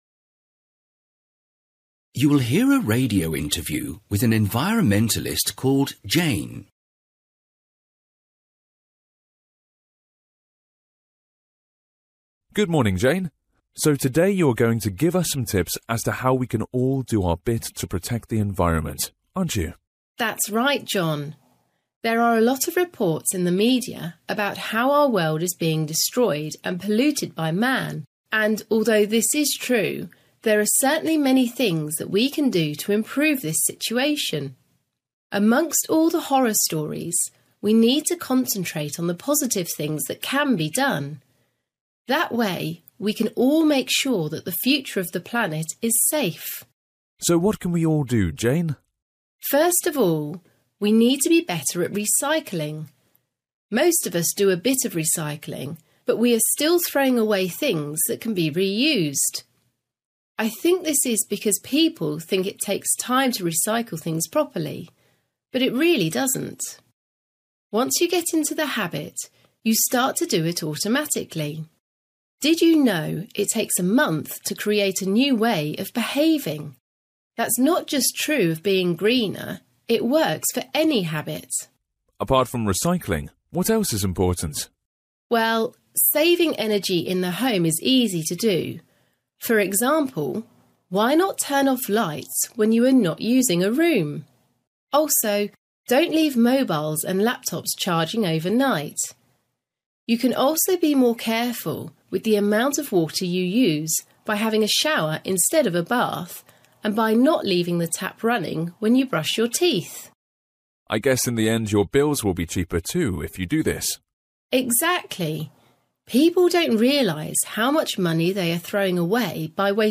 Listening: an environmentalist